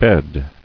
[bed]